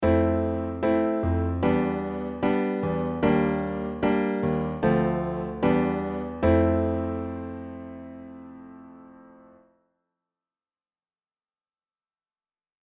逆にＢは儚く趣がある感じがするね。